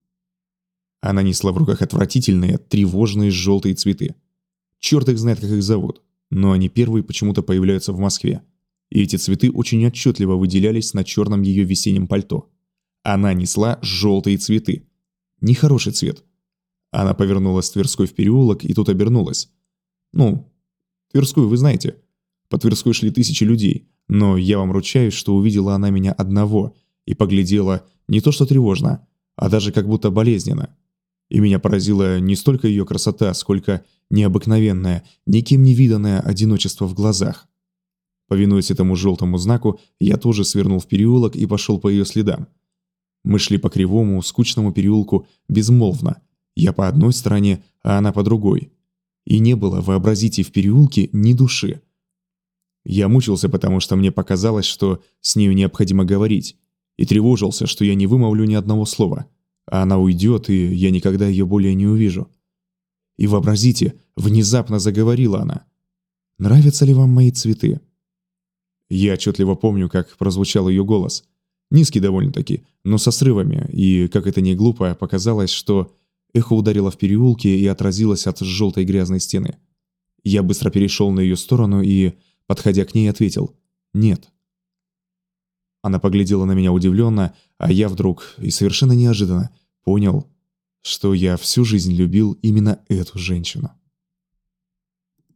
Аудиокнига
Муж, Аудиокнига/Средний